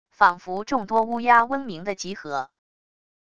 仿佛众多乌鸦嗡鸣的集合wav音频